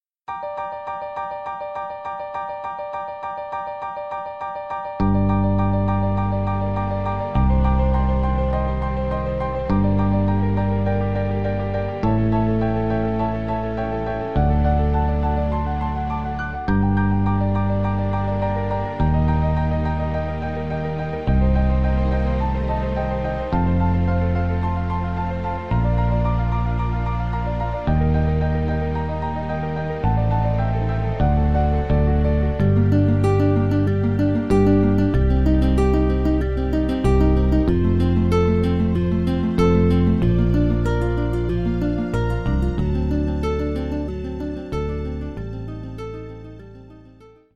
(sans voix féminine)